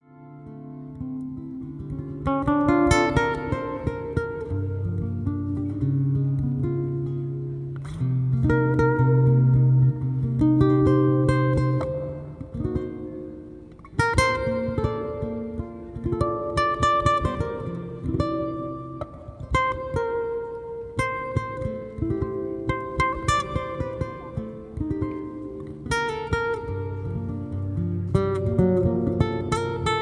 Live in Little Tokyo
Solo Guitar Standards
Soothing and Relaxing Guitar Music